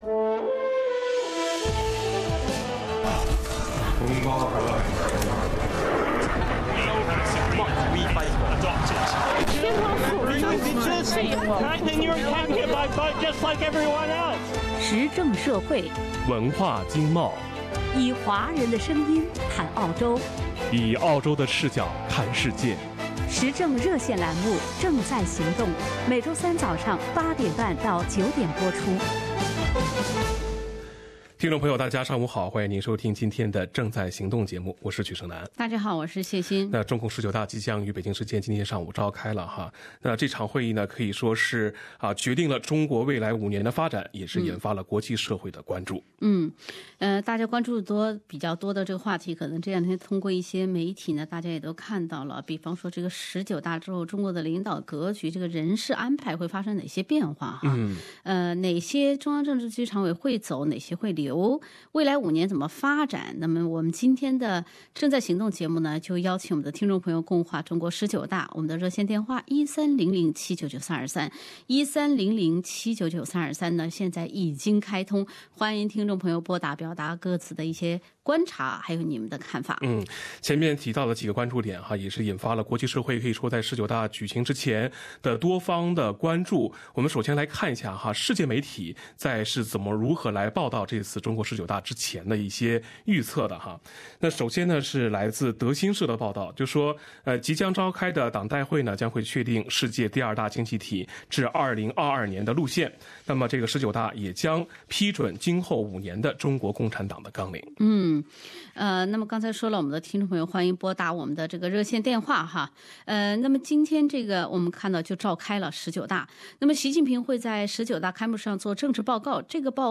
正在行动 - 谁主沉浮？听众热议中共十九大